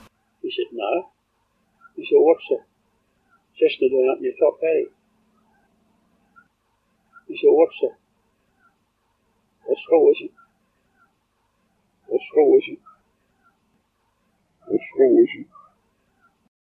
AUDIO ENHANCED